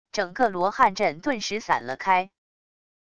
整个罗汉阵顿时散了开wav音频生成系统WAV Audio Player